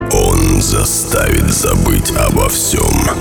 Sms сообщение
грубый голос